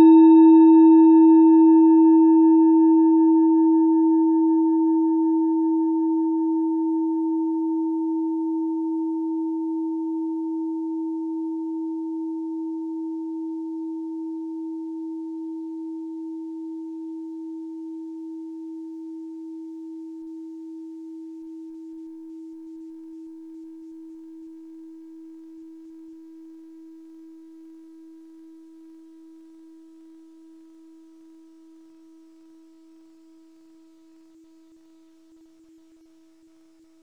Kleine Klangschale Nr.34 Nepal, Planetentonschale: Alpha-Welle
(Ermittelt mit dem Minifilzklöppel)
Der Klang einer Klangschale besteht aus mehreren Teiltönen.
Die Klangschale hat bei 320.8 Hz einen Teilton mit einer
Die Klangschale hat bei 323.24 Hz einen Teilton mit einer
kleine-klangschale-34.wav